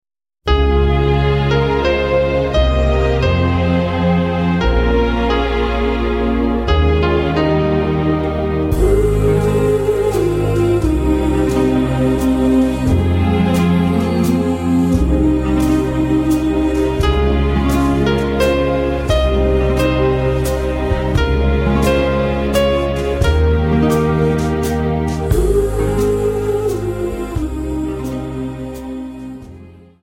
Dance: Slow Waltz 29 Song